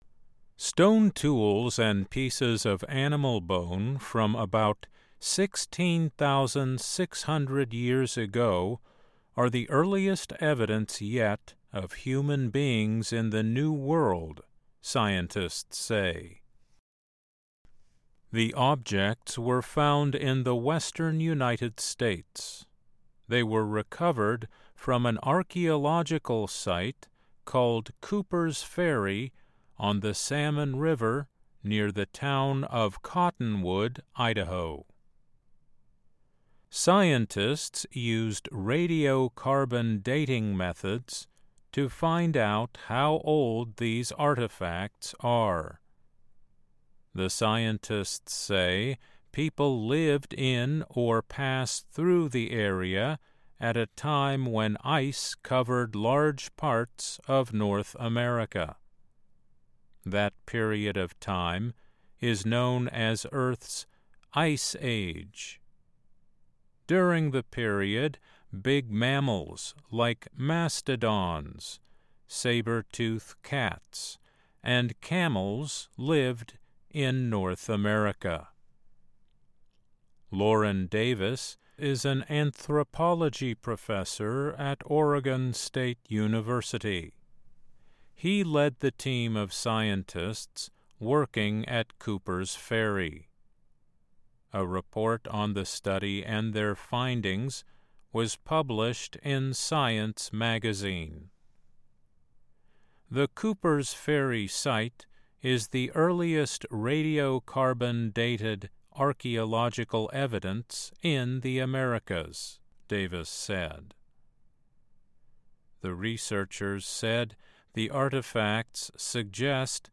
慢速英语:科学家称美洲的第一批人至少在16600年前到达